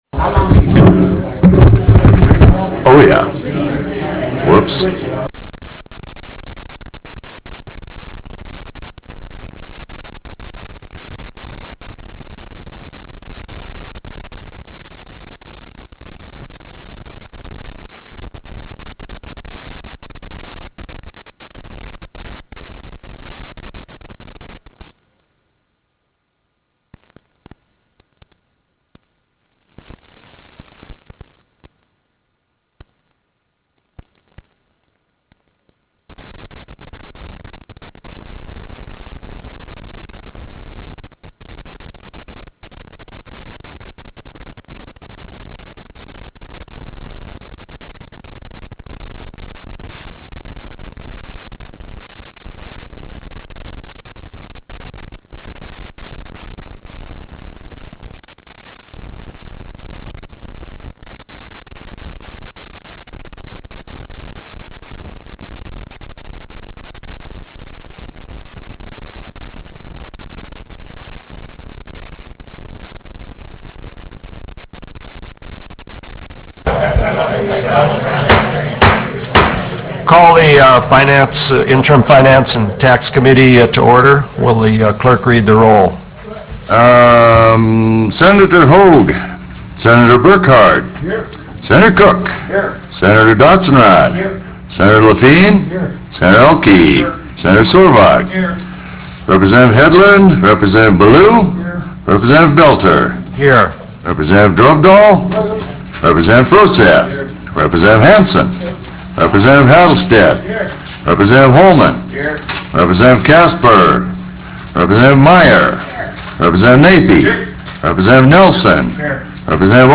Roughrider Room State Capitol Bismarck, ND United States